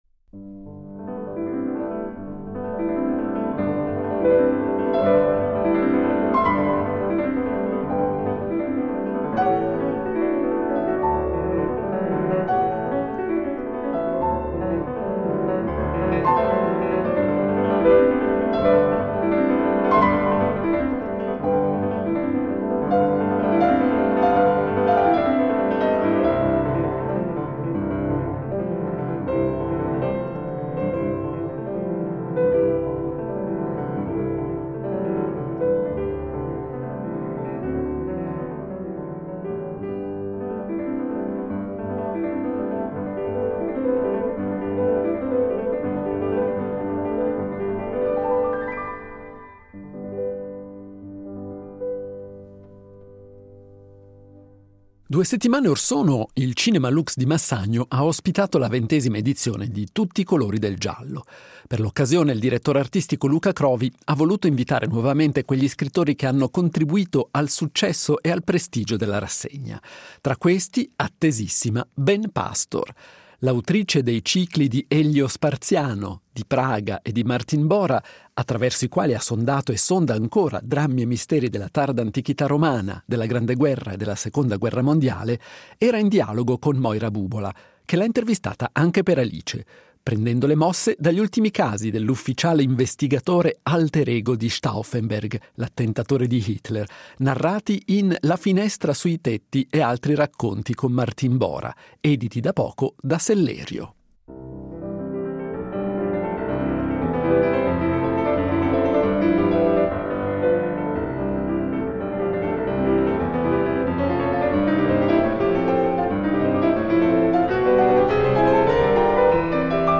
Intervista integrale.